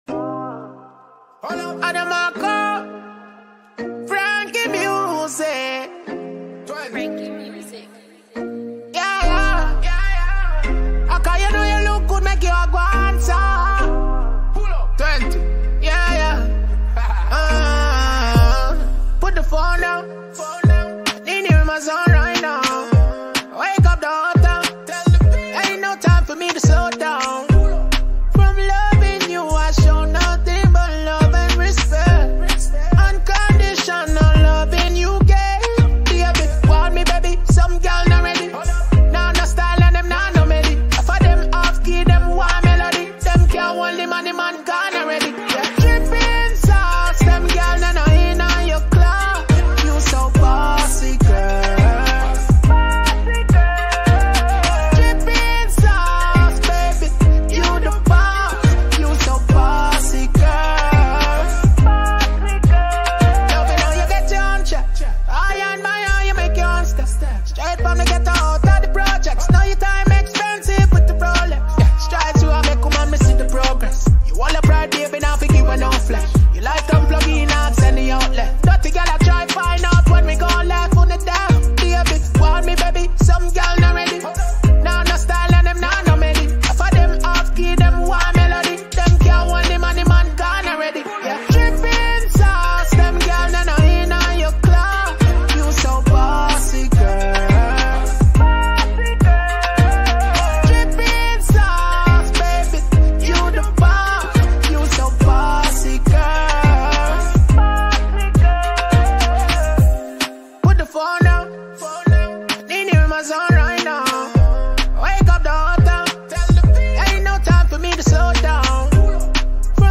Dancehall/HiphopMusic
Jamaican top-notch recording reggae dancehall artiste